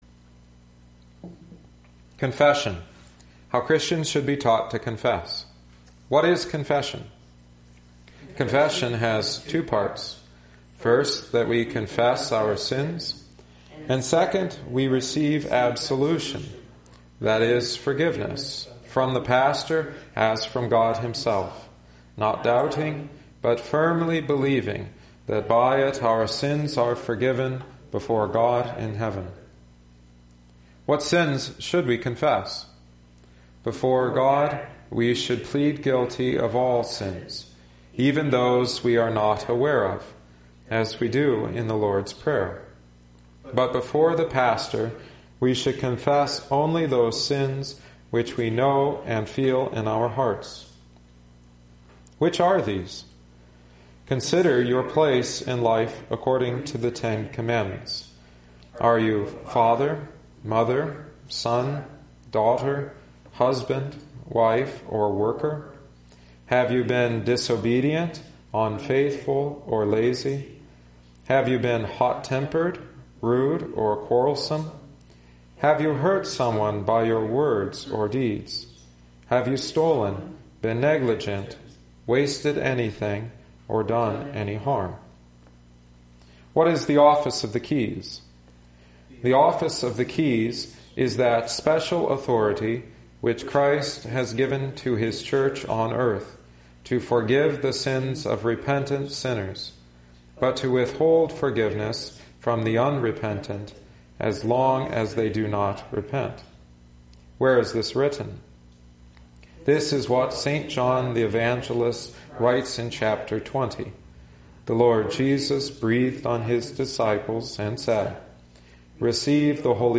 Psalm 132 (antiphon: v. 13) Office Hymn: 426 When I Survey the Wondrous Cross OT: 2 Sam 11:2-5,14-17,26-12:7,13-14 Ep: James 5:13-20 Gospel: Jn 20:19-23 Catechetical Hymn: 610 Lord Jesus, Think On Me